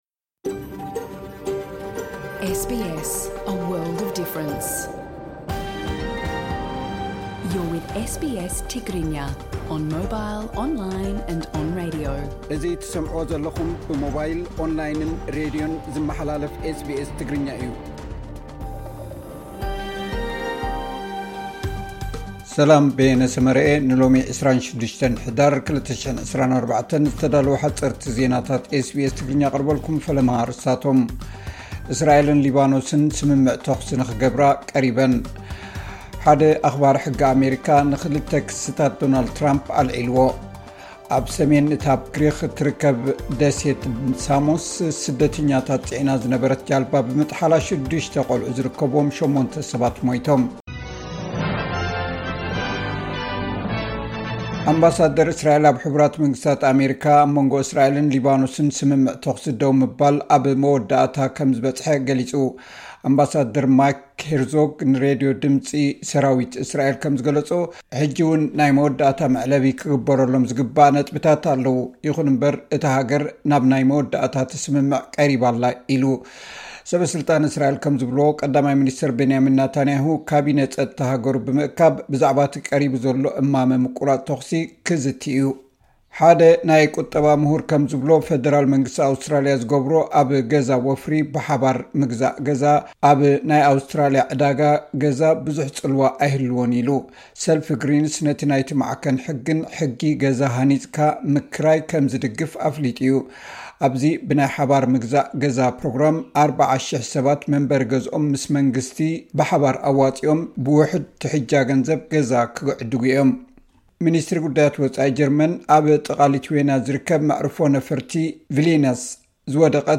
ሓጸርቲ ዜናታት ኤስ ቢ ኤስ ትግርኛ (26 ሕዳር 2024)